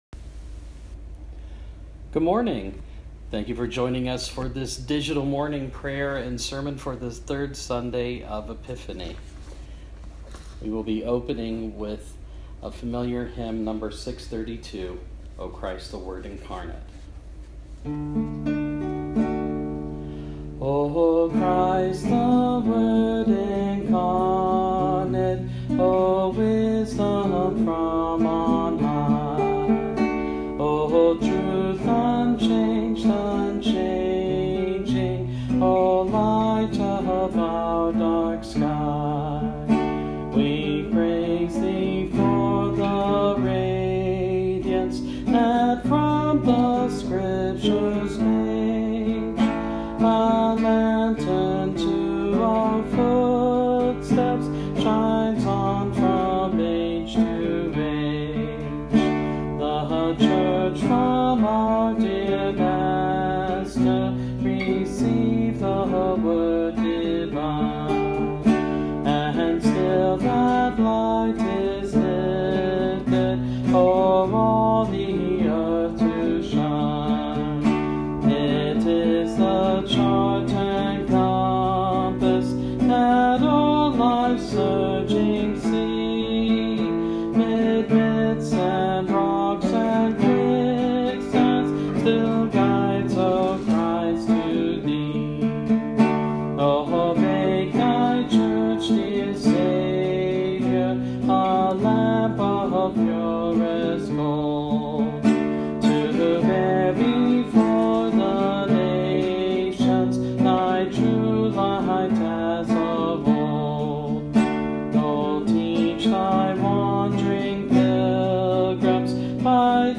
So here is a short service of Morning Prayer with Sermon and a little bit of music.
(Please excuse my flub during the last hymn when I had sudden dry eye and everything went blurry while I was singing.) May you find the prospect of God speaking to you in nature and Scripture so exciting that you are energized on your spiritual journey.
3rd-sunday-after-epiphany-morning-prayer.m4a